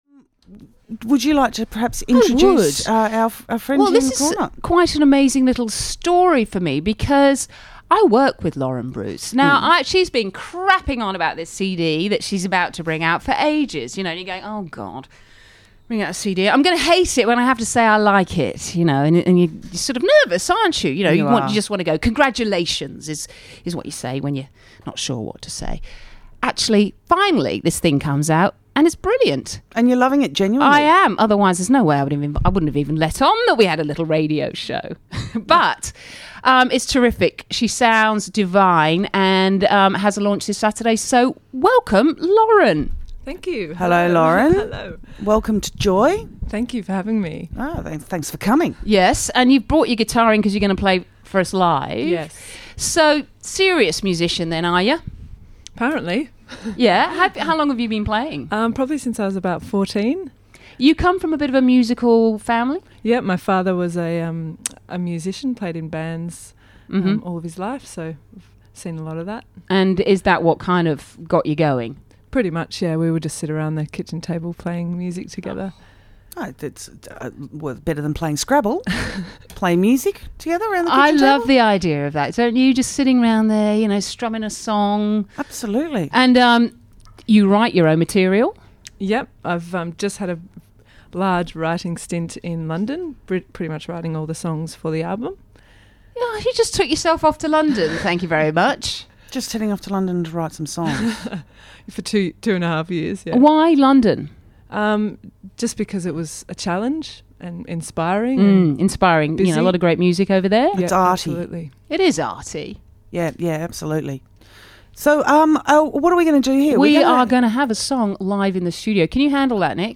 Live on Melbourne breakfast radio